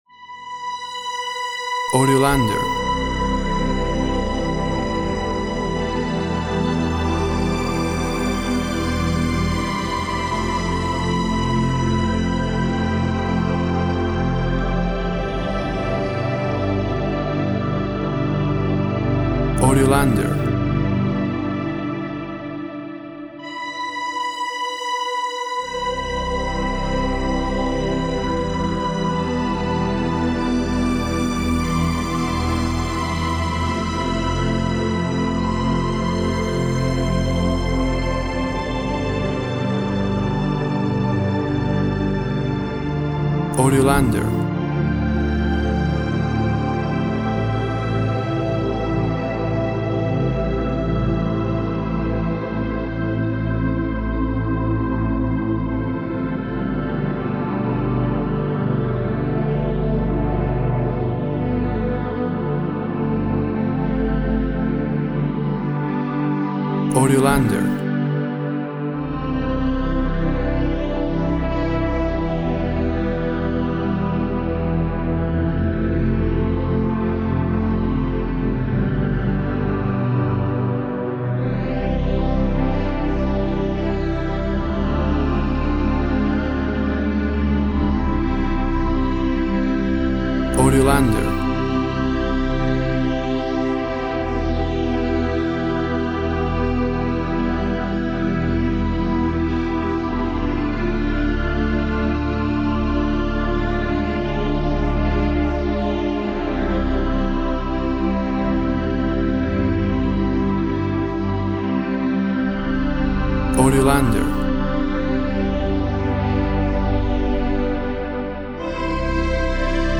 WAV Sample Rate 24-Bit Stereo, 44.1 kHz
Tempo (BPM) 48